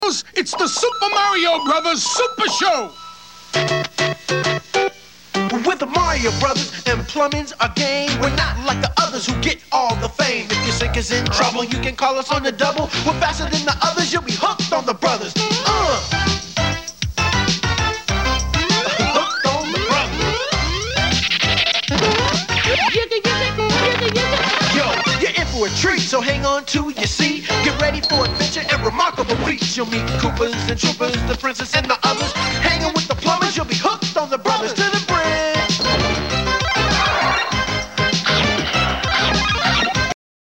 • Video games